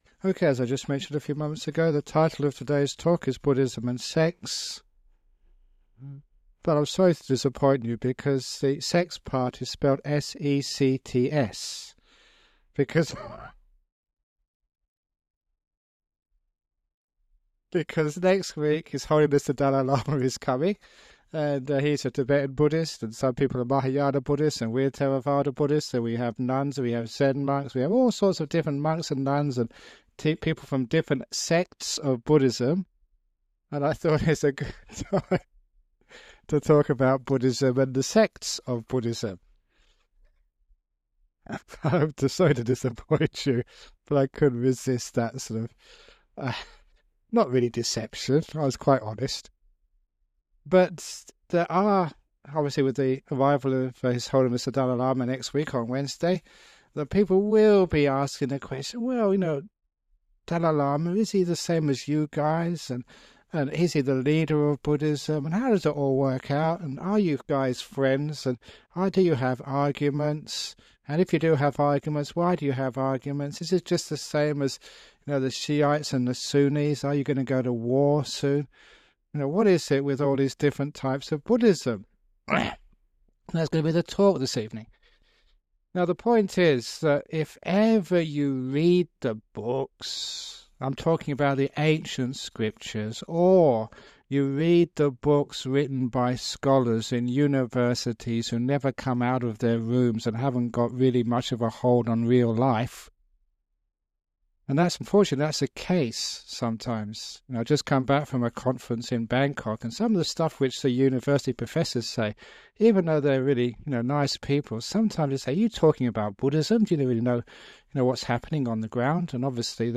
The title of the talk is Buddhism and Sex, but it is actually about the different sects of Buddhism. The speaker explains that there should be no judgment or hierarchy between different traditions and that it is more important to focus on how one uses the teachings.